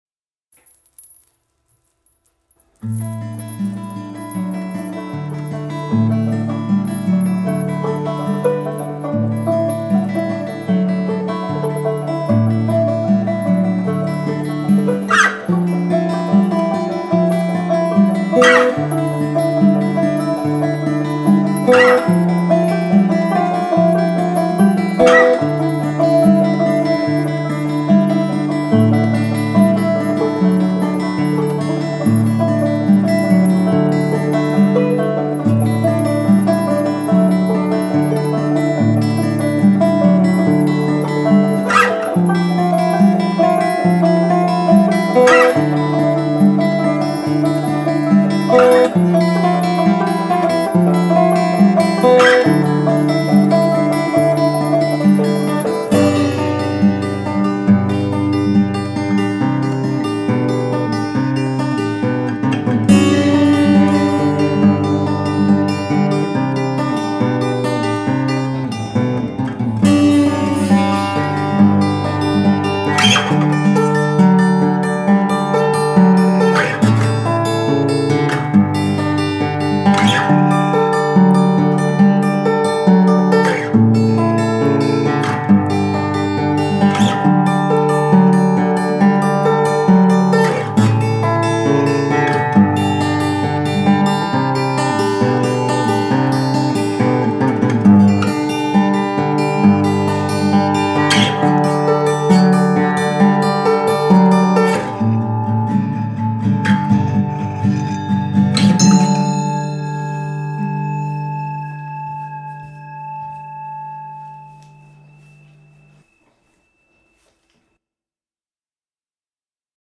guitar
banjo
bulbul tarang